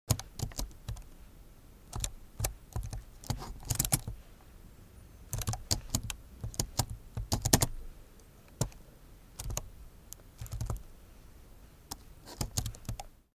جلوه های صوتی
دانلود صدای کیبورد 10 از ساعد نیوز با لینک مستقیم و کیفیت بالا